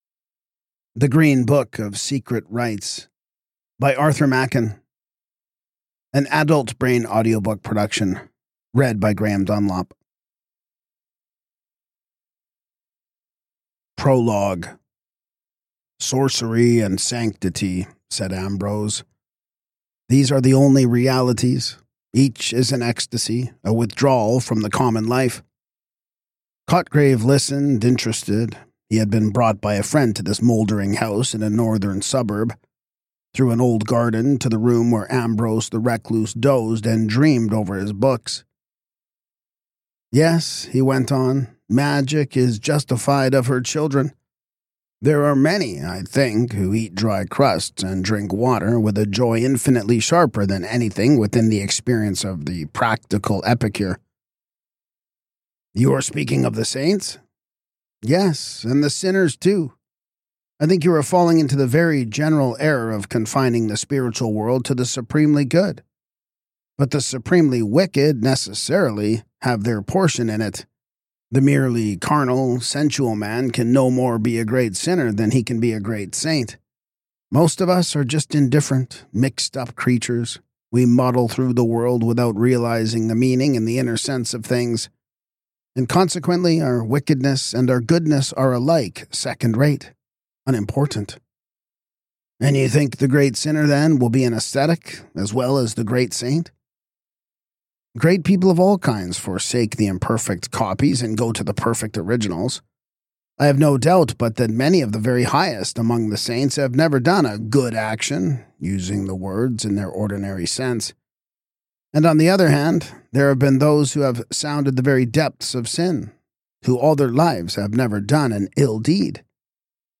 Podcast (audiobooks): Play in new window | Download